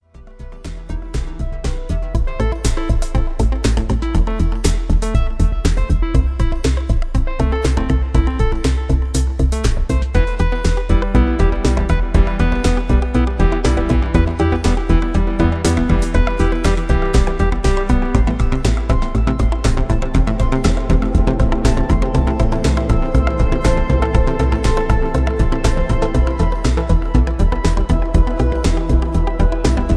Electronic Ambiental ARP Loop
Tags: arp